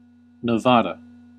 Ääntäminen
Ääntäminen US Tuntematon aksentti: IPA : /nɨˈvæːdə/ IPA : /nəˈvɑ.də/ Haettu sana löytyi näillä lähdekielillä: englanti Käännös Erisnimet 1. Nevada {das} Määritelmät Erisnimet A Capital: Carson City .